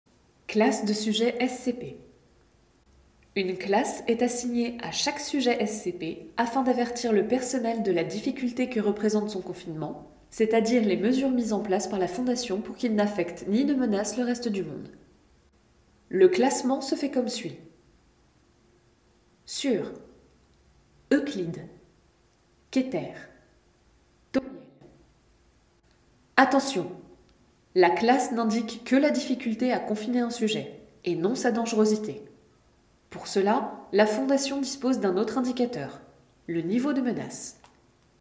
Voix intonation d'Intelligence artificielle
10 - 40 ans - Mezzo-soprano